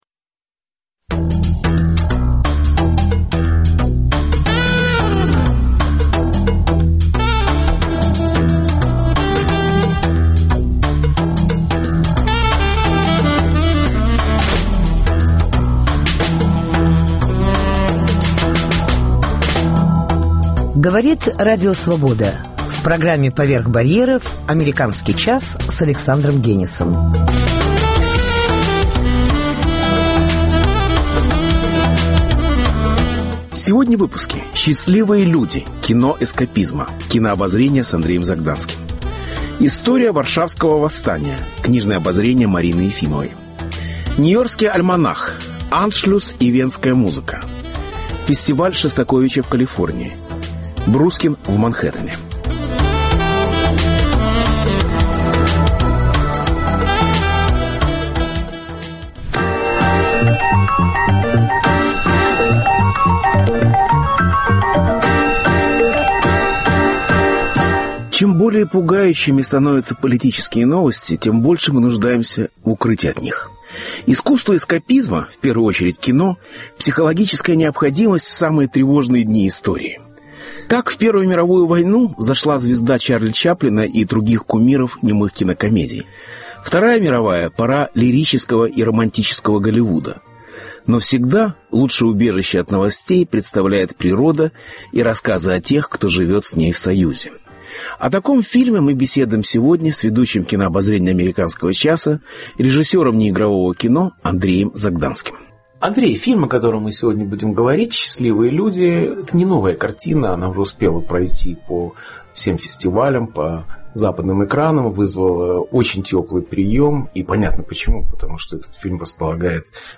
Кинообозрение